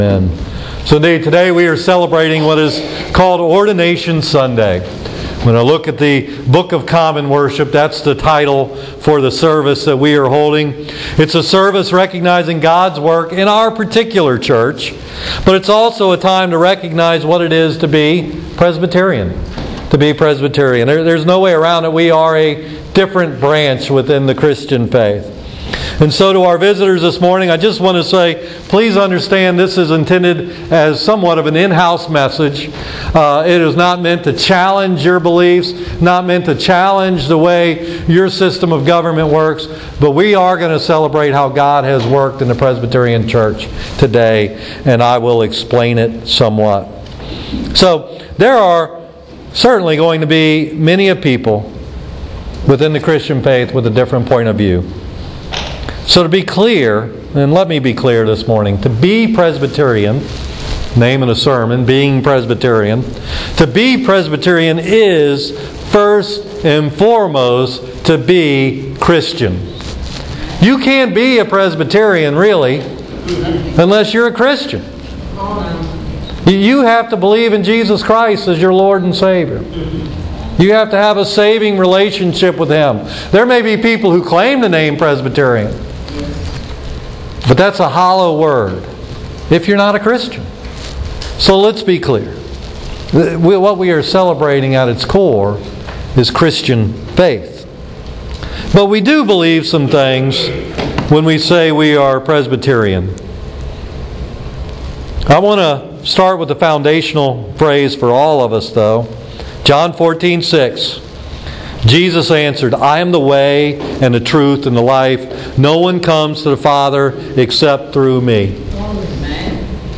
Ordination Services
ordination-sunday-2016.mp3